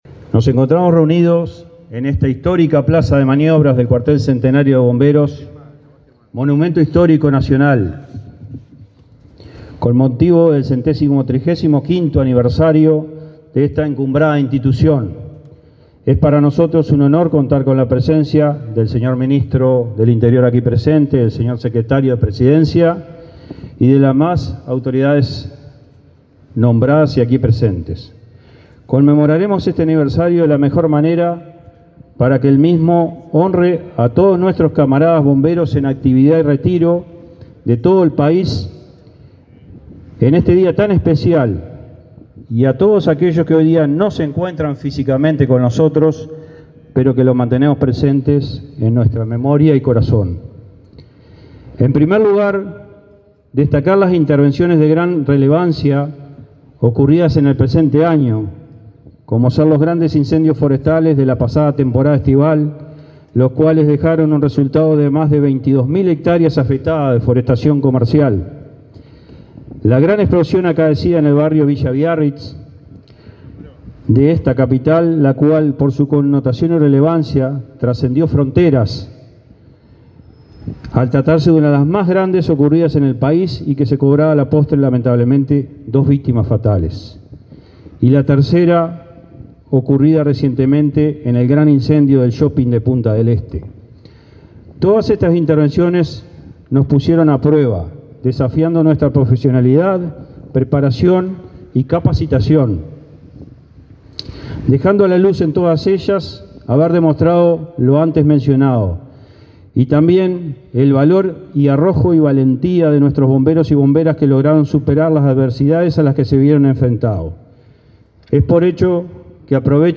Palabras de autoridades en aniversario de la Dirección Nacional de Bomberos
El ministro del Interior, Luis Alberto Heber, y el titular de la Dirección Nacional de Bomberos, Ricardo Riaño, fueron los oradores del acto